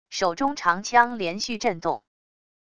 手中长枪连续震动wav音频